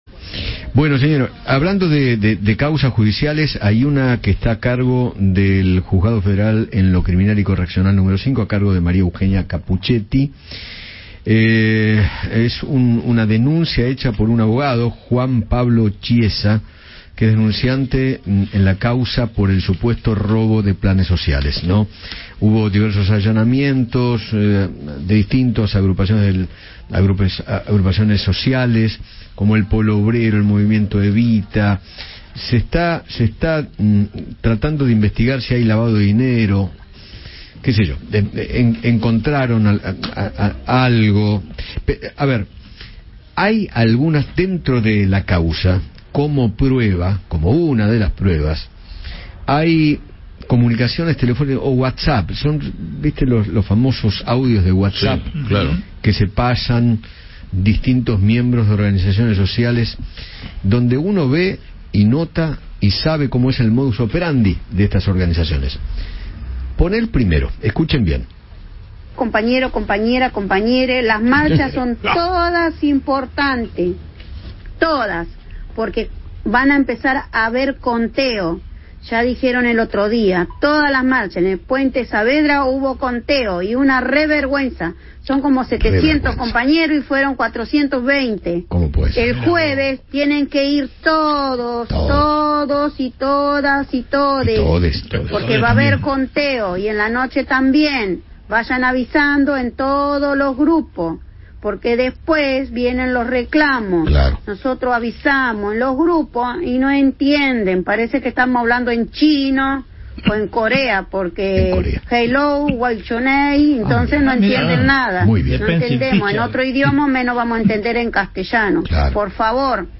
charló con Eduardo Feinmann acerca de los datos que recolectó la empresa sobre la cantidad de piquetes que podrían llevarse a cabo en el país.